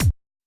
kick.wav